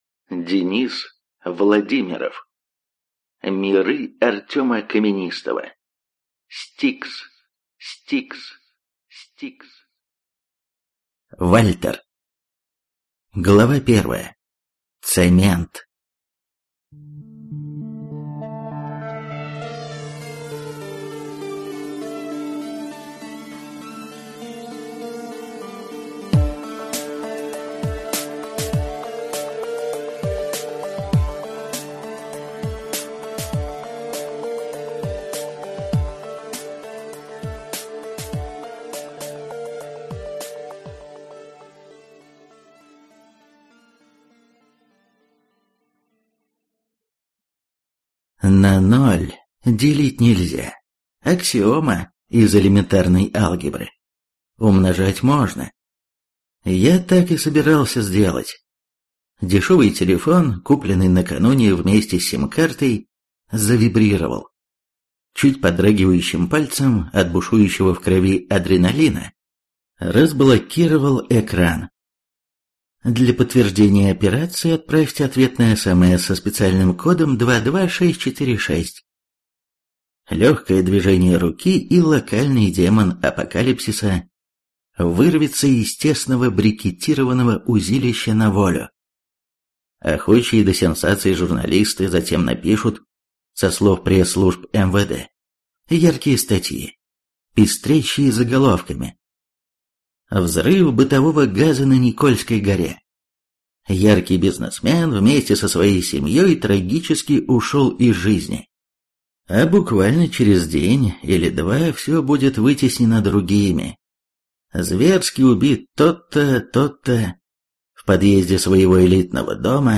Аудиокнига S-T-I-K-S. Вальтер | Библиотека аудиокниг